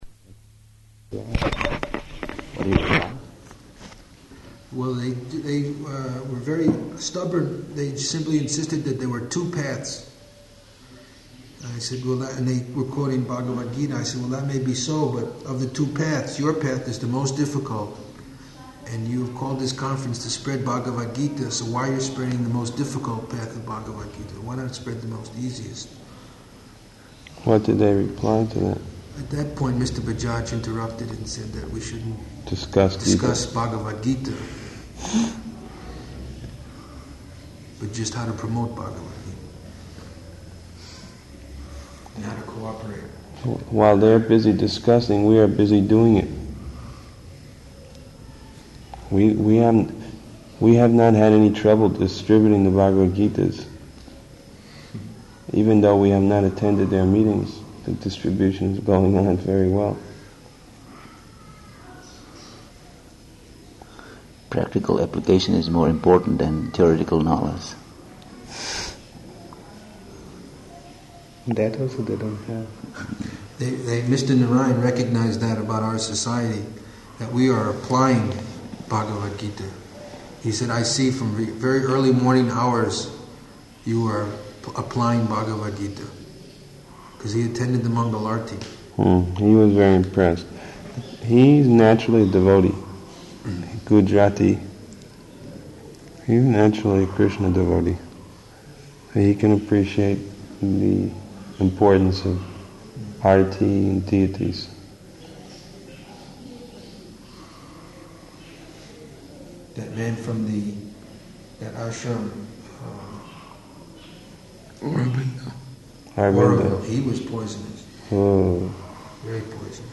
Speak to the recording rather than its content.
-- Type: Conversation Dated: November 3rd 1977 Location: Vṛndāvana Audio file